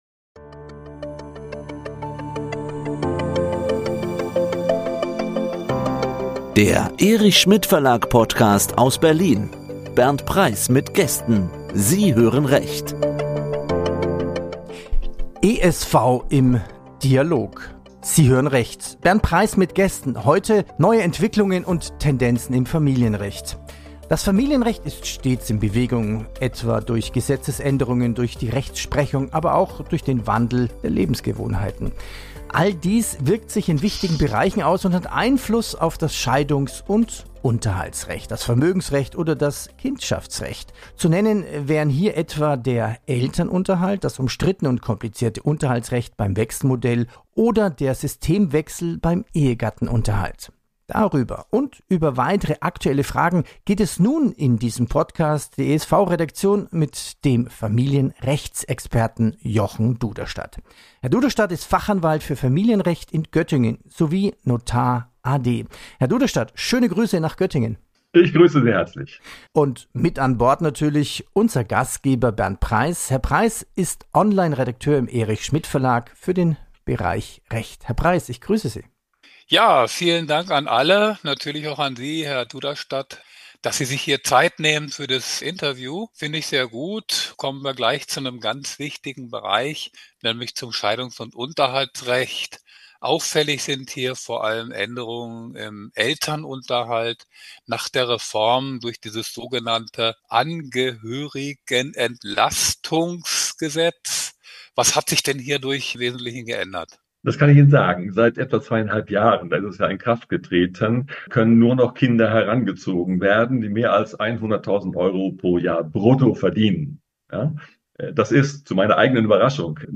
Zu nennen wären hier etwa der Elternunterhalt, das umstrittene und komplizierte Unterhaltsrecht beim Wechselmodell oder der Systemwechsel beim Ehegattenunterhalt. Hierüber und über weitere aktuelle Fragen hat sich die ESV-Redaktion mit dem Familienrechtsexperten